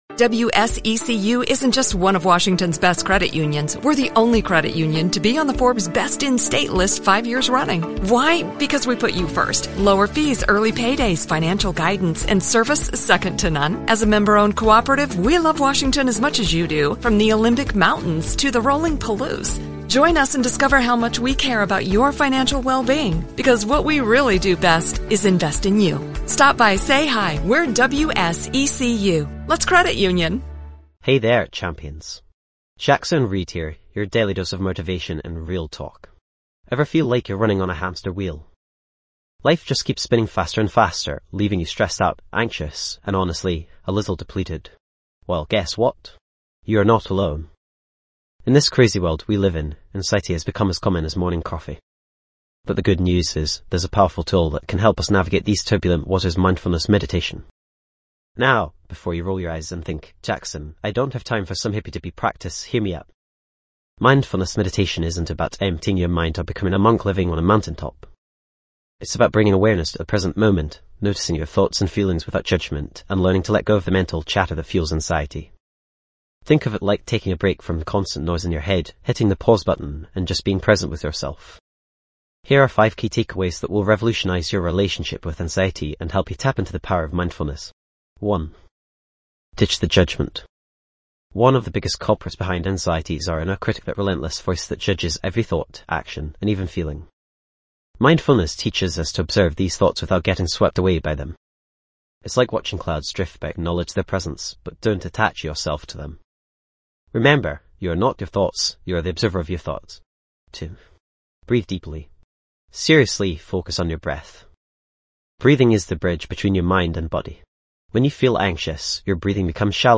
Unwind your mind and soothe your nerves with a guided mindfulness meditation designed to alleviate anxiety.
This podcast is created with the help of advanced AI to deliver thoughtful affirmations and positive messages just for you.